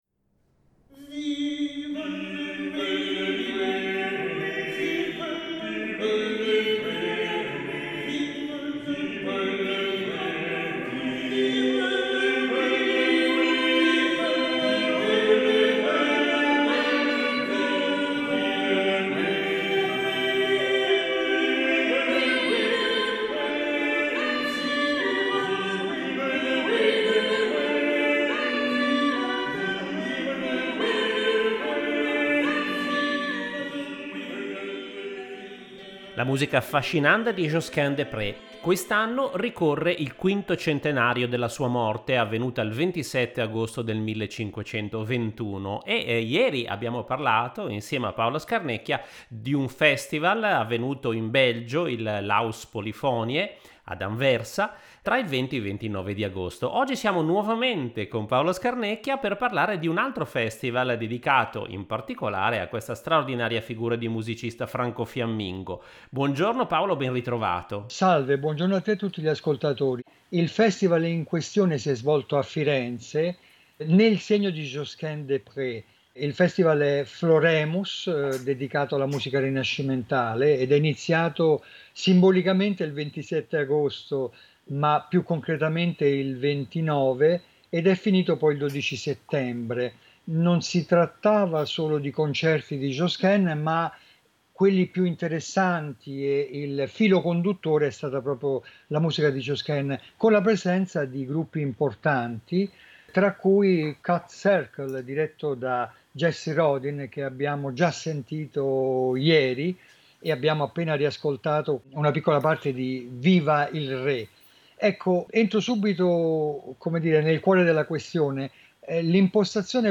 ascolteremo le testimonianze di alcuni protagonisti di queste due manifestazioni, ed estratti dei più importanti concerti registrati live ad Anversa e a Firenze dei gruppi Huelgas Ensemble, Cappella Pratensis, Cut Circle, Ensemble Clement Janequin e L’Homme Armé.